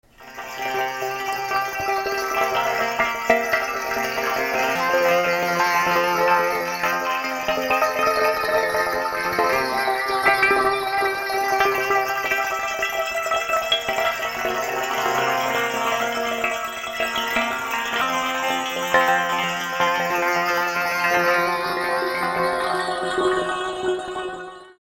The instrument can also be set so that any sound is the source.
example, a synthesized model of bamboo wind-chimes is controlled by the pressure of the pen and used to "ring" the chords.